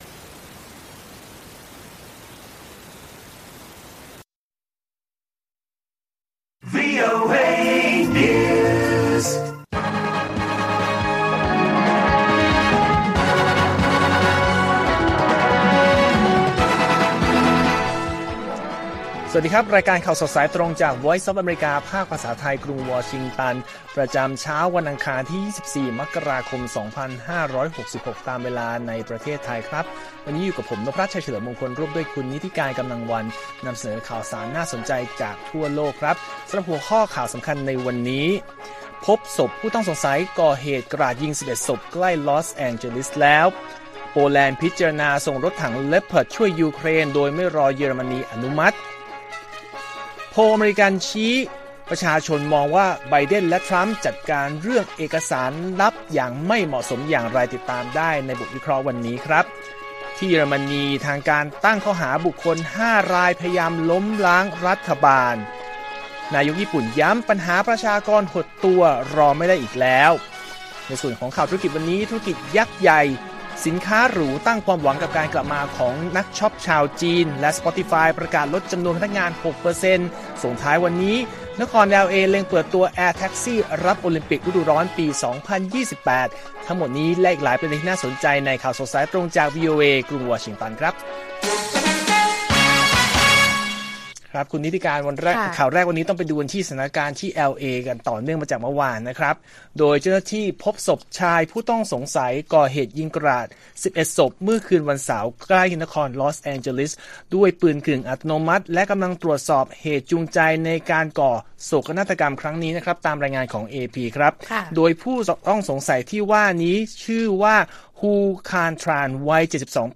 ข่าวสดสายตรงจากวีโอเอ ไทย อังคาร 24 มกราคม 2566